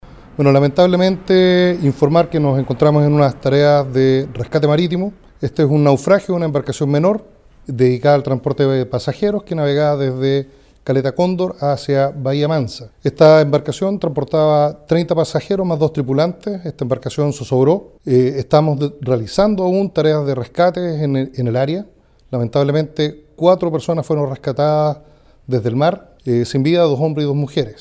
A su vez, el gobernador marítimo de Los Lagos, Mario Besoain, precisó que la lancha tenía su permiso de zarpe al día, vigente hasta el 31 de enero.